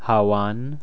1. Vowel Nasalization
All vowels are nasalized before nasal consonants.
Example: /hawan/ --> [
hawn] 'cold'